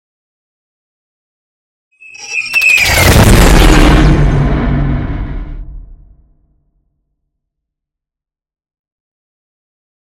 Scifi passby whoosh
Sound Effects
futuristic
intense
pass by
vehicle